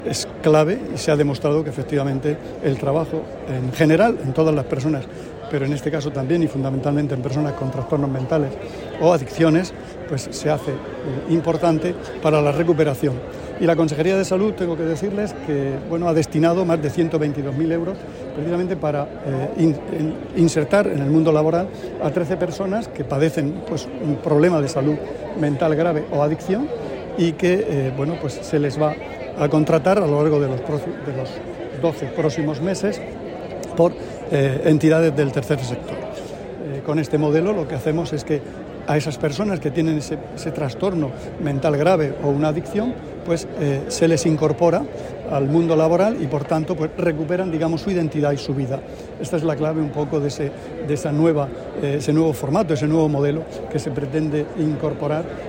Sonido/ Declaración del consejero de Salud, Juan José Pedreño [mp3], en el acto de conmemoración por el Día Mundial de la Salud Mental.